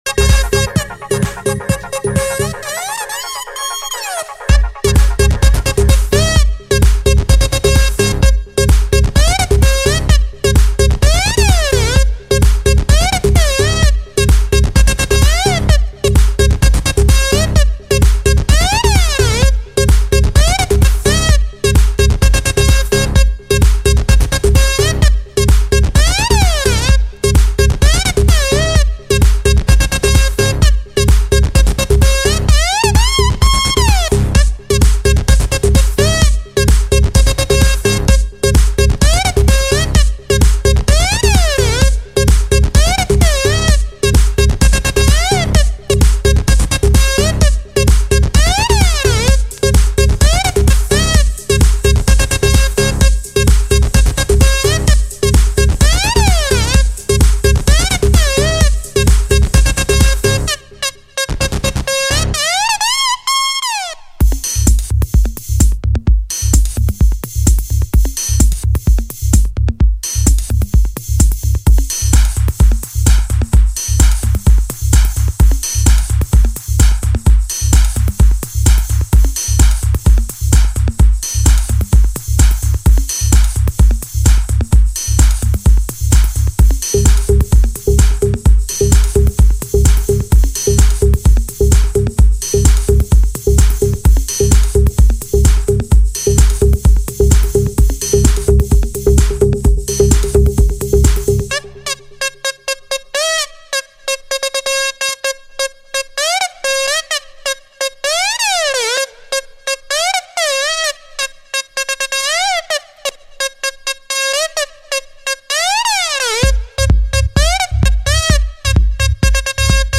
Список файлов рубрики Клубная
Електруха , ну послушать